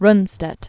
(rnstĕt, rntshtĕt), Karl Rudolf Gerd von 1875-1953.